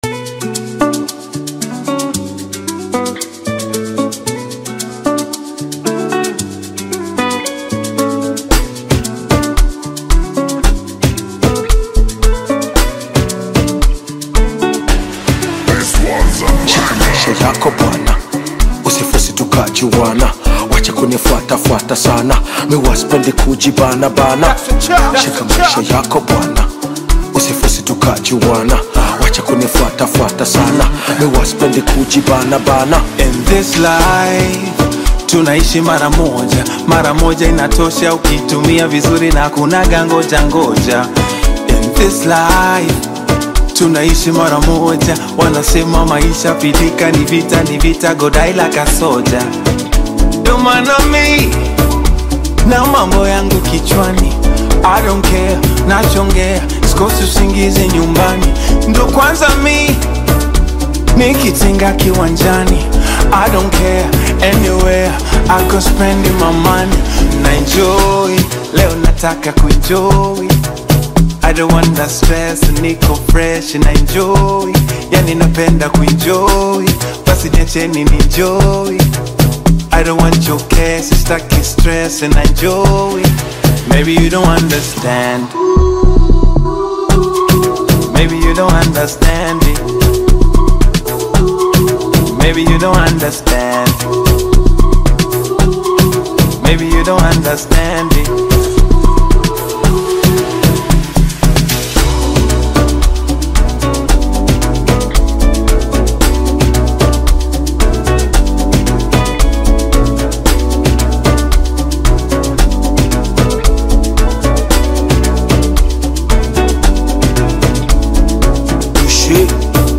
creating an upbeat and catchy audio experience.
with vibrant beats and dynamic vocals
Tanzanian music